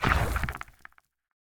sounds / mob / warden / step_1.ogg
step_1.ogg